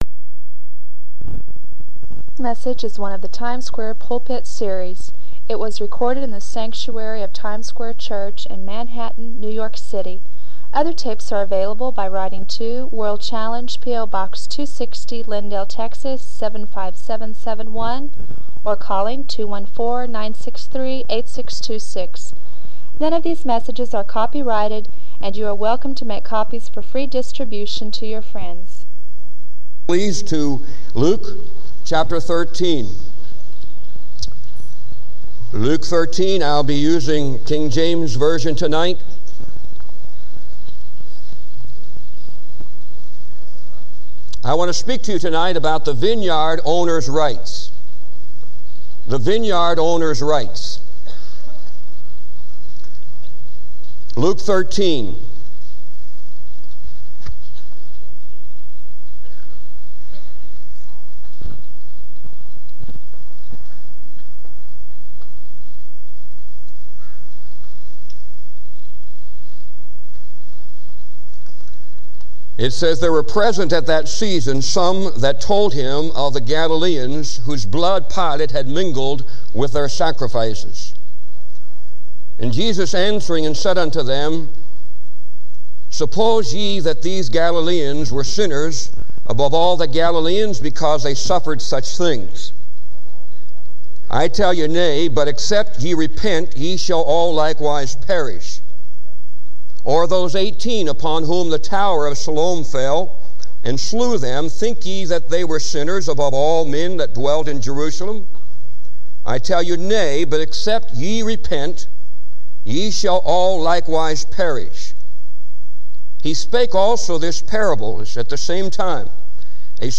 This sermon calls believers to personal responsibility and spiritual growth under God's authority.